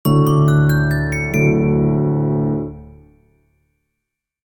ジングル[ピアノ・ベル音色](1) ちょっとアラビアン